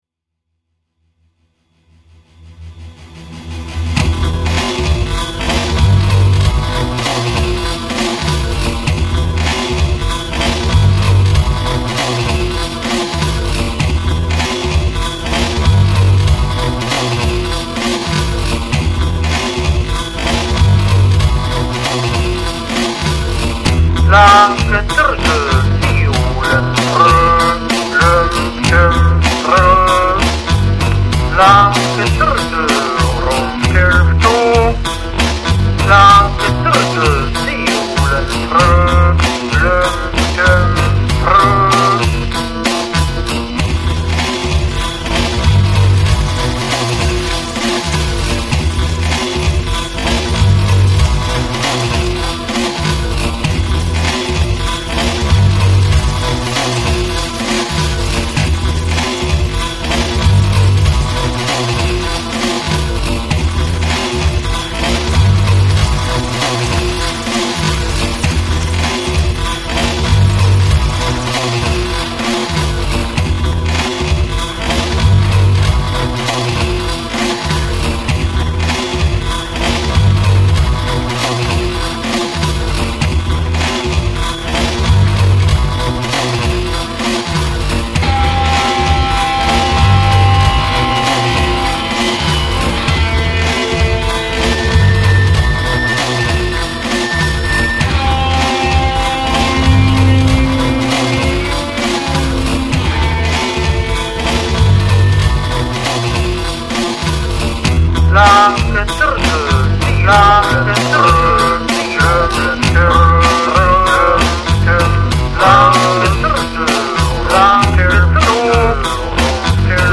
A dark, loping waltz with mind control space aliens.